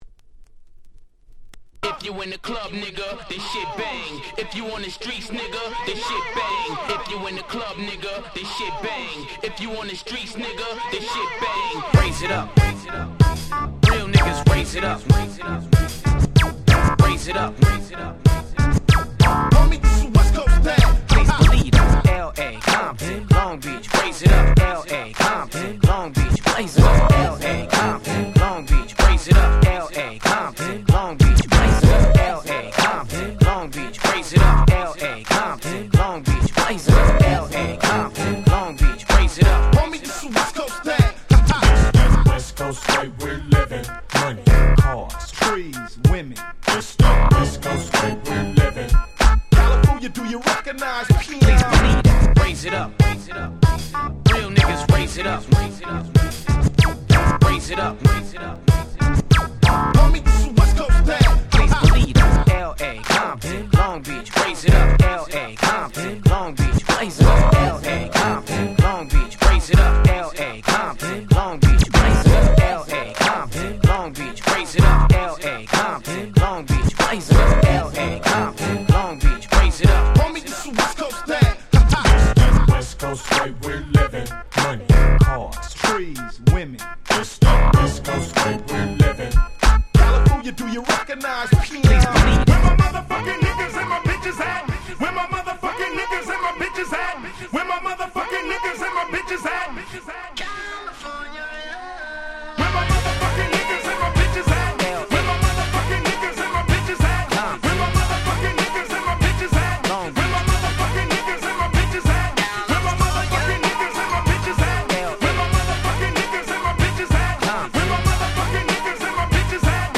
03' Very Nice Hip Hop Party Tracks !!